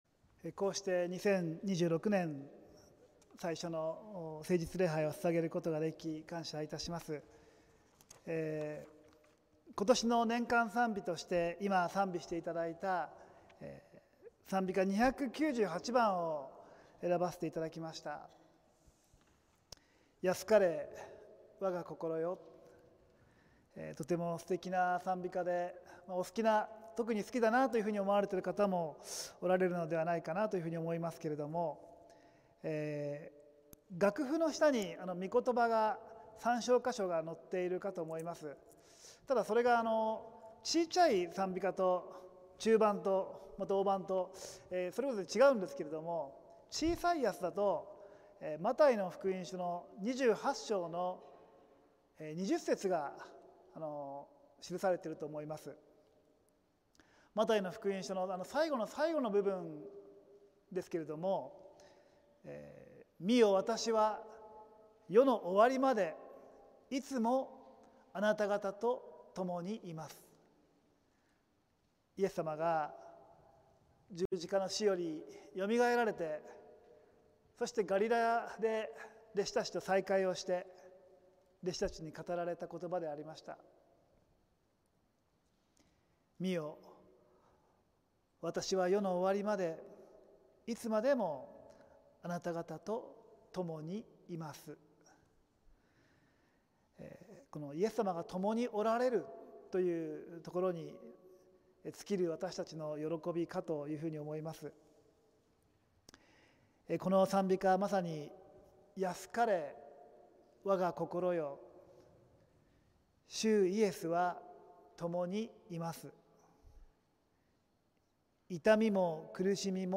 浦和福音自由教会(さいたま市浦和区)の聖日礼拝(2026年1月4日)「安かれ 我が心よ」(週報とライブ/動画/音声配信)